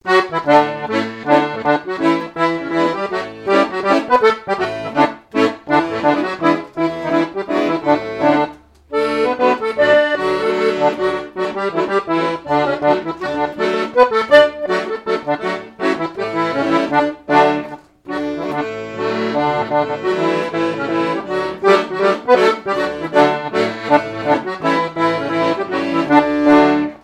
danse : scottish
airs de danses issus de groupes folkloriques locaux
Pièce musicale inédite